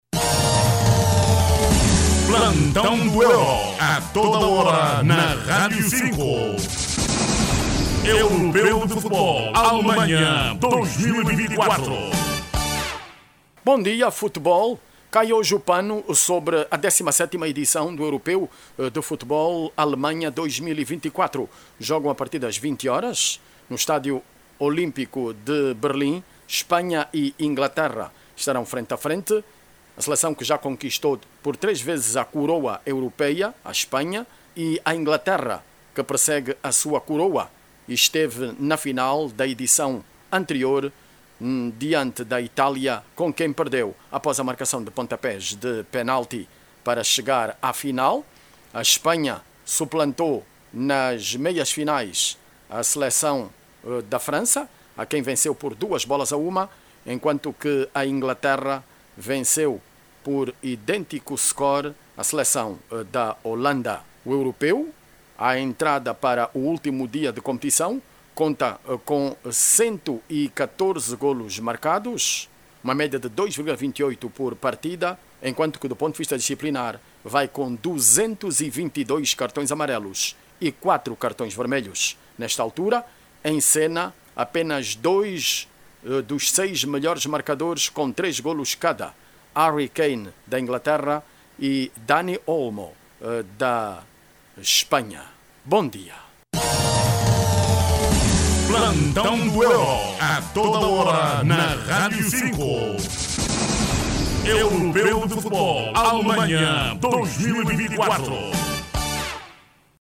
A final, vai proporcionar o desafio entre a Inglaterra e a Espanha hoje, domingo, 14 de Julho em evidência pelo título da competição. Ouça, no áudio abaixo, toda informação com a reportagem